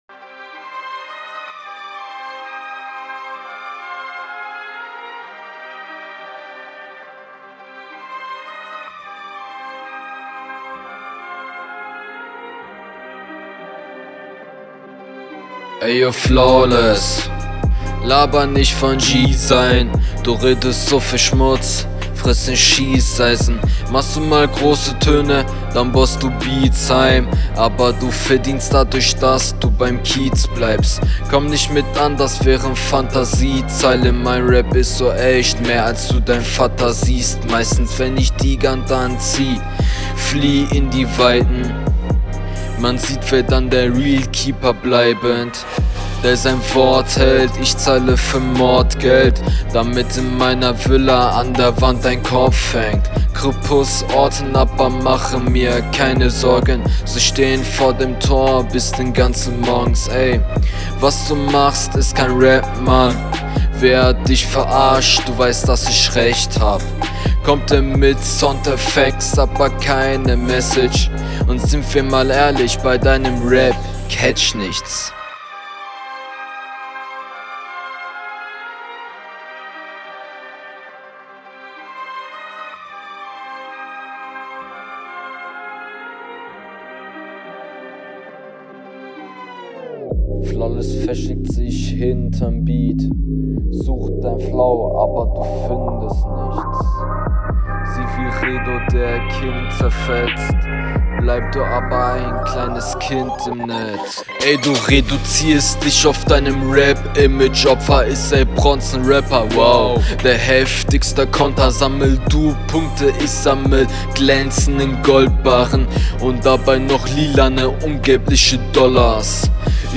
Sprachlich schwer zu verstehen und flowtechnisch auch nicht geil es bleibt keine Line hängen!